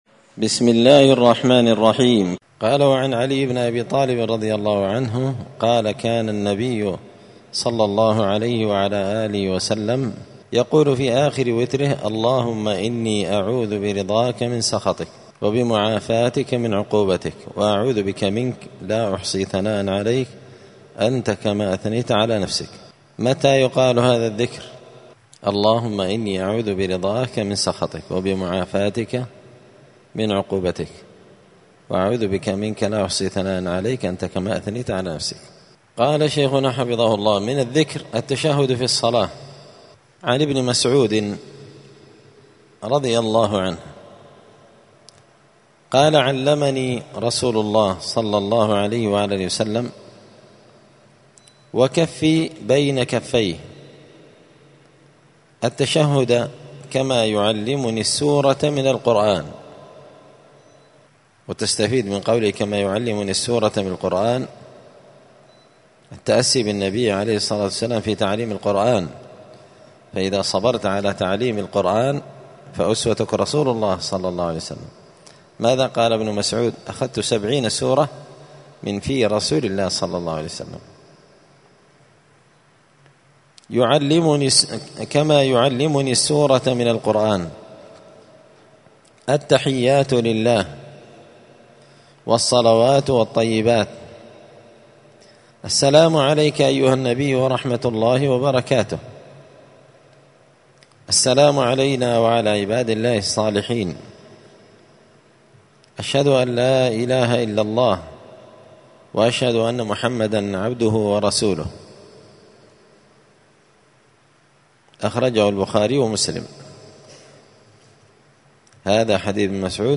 *{الدرس الرابع والثلاثون (34) أذكار الصلاة من الذكر التشهد في الصلاة}*